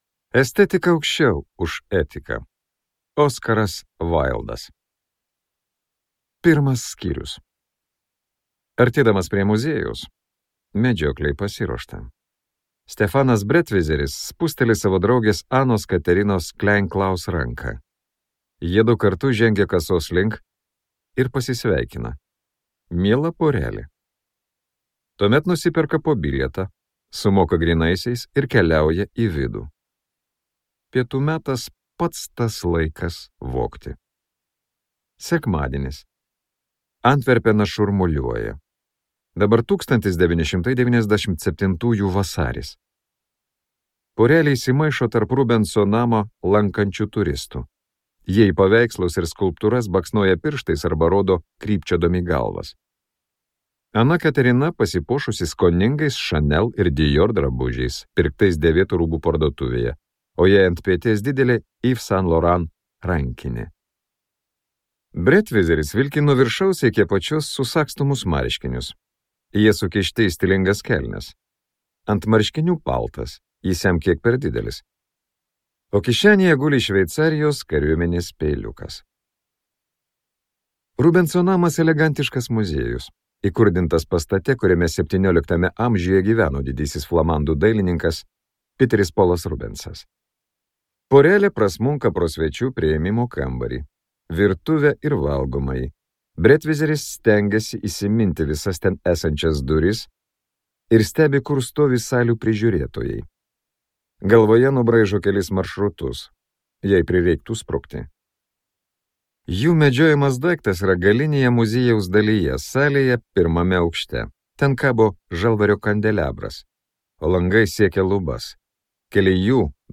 Meno vagis | Audioknygos | baltos lankos